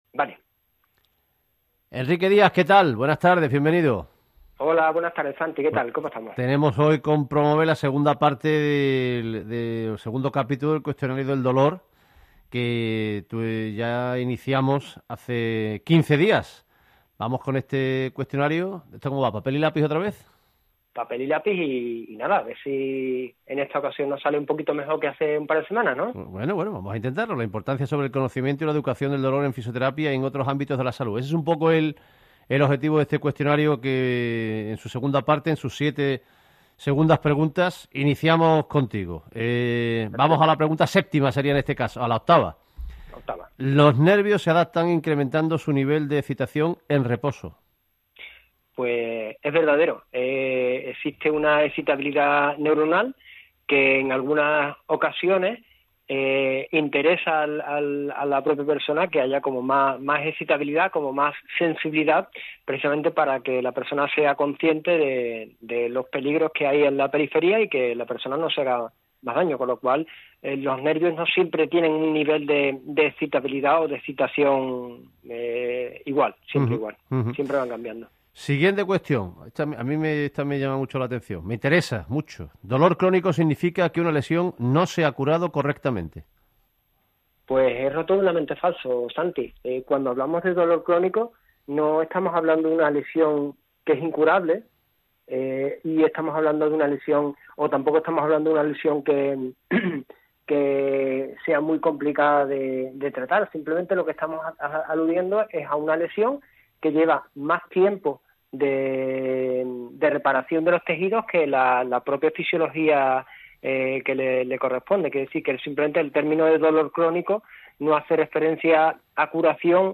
Tienes en esta entrega la segunda parte del cuestionario sobre dolor emitido en la radio en Cadena SER.
Emitido el 22 de mayo de 2022 en el programa Ser Deportivos Andalucía.